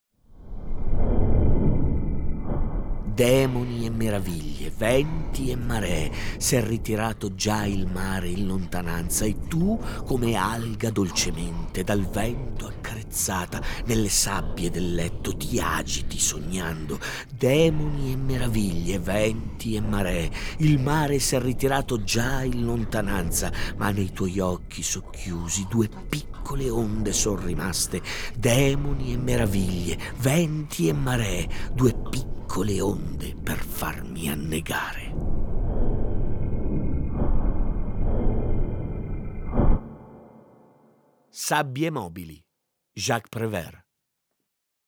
Letto da